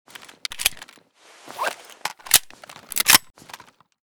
kiparis_reload_empty.ogg